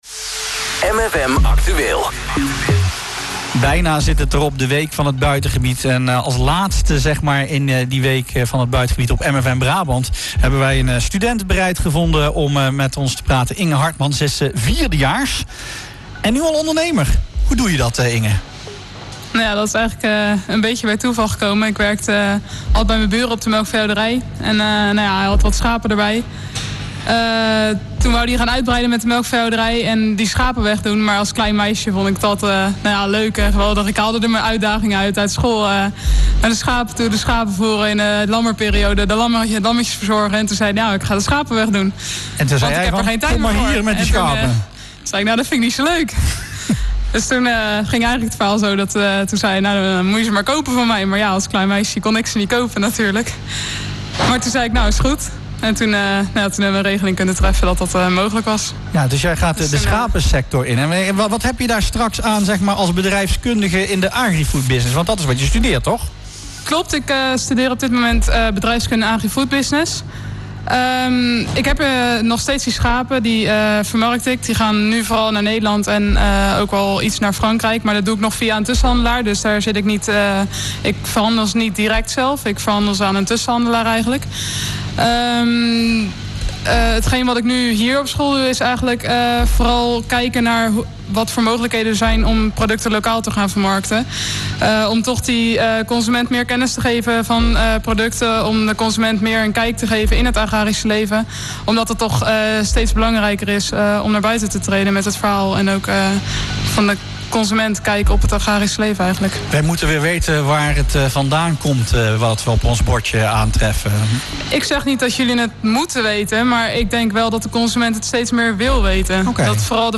De week werd vrijdag (26 oktober) afgesloten op de HAS Den Bosch. Daar stond de innovatie van de landbouwsector centraal.